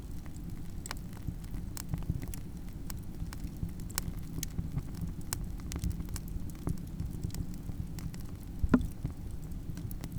Fire-Small.wav